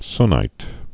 (snīt)